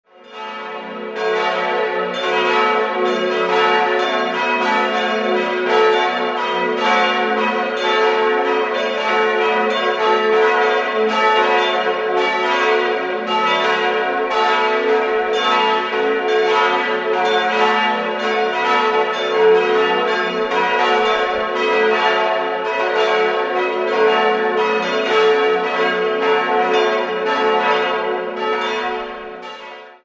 In den Jahren 1947 bis 1950 entstand das heutige Gotteshaus, wobei man Ausstattungsstücke der Vorgängerkirche übernahm. 4-stimmiges Fis-Moll-Geläute: fis'-a'-h'-cis'' Die vier Glocken aus der Gießerei Friedrich Wilhelm Schilling (Heidelberg) wurden 1961 gegossen und wiegen 1076, 627, 416 und 300 kg.